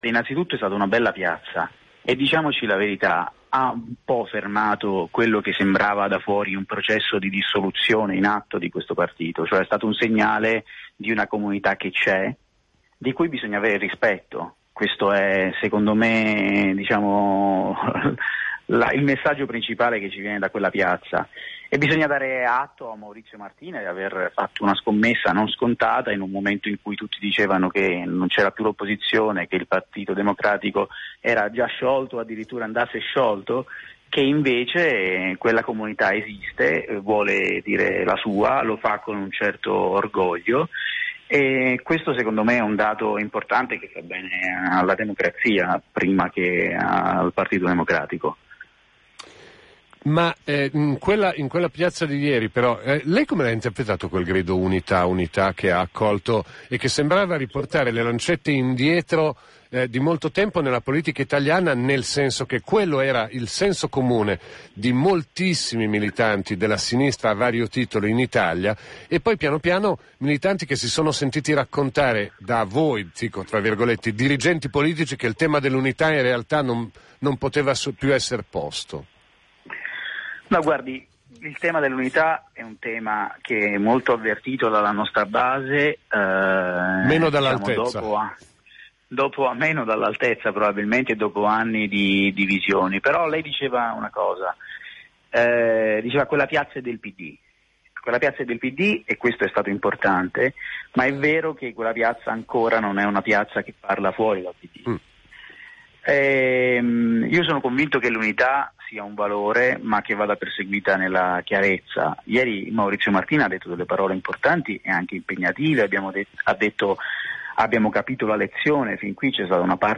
Oggi abbiamo intervistato l’economista Giuseppe Provenzano, vicedirettore dello Svimez e membro di minoranza della Direzione del Partito Democratico, che ci ha parlato del futuro del Partito Democratico e di come bisogna intervenire per riacquistare forza e credibilità agli occhi degli elettori e dell’opinione pubblica.
intervista-Giuseppe-Provenzano.mp3